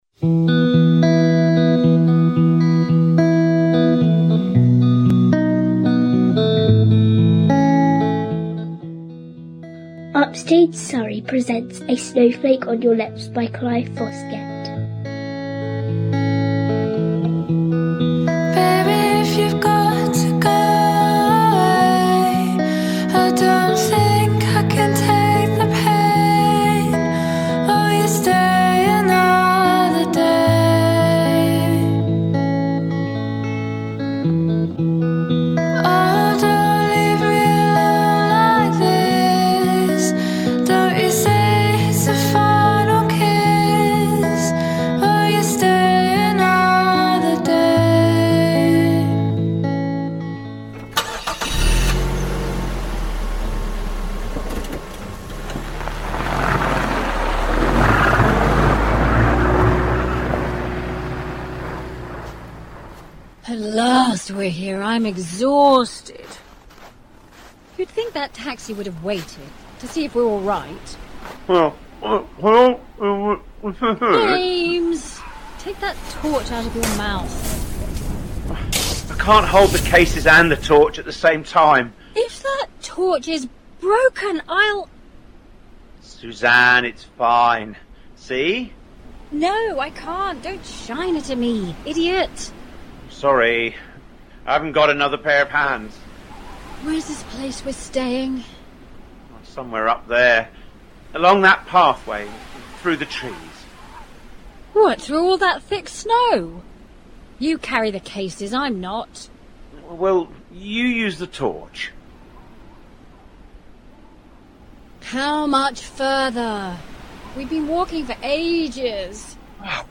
audio Christmas play